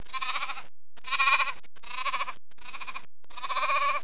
جلوه های صوتی
دانلود صدای حیوانات جنگلی 111 از ساعد نیوز با لینک مستقیم و کیفیت بالا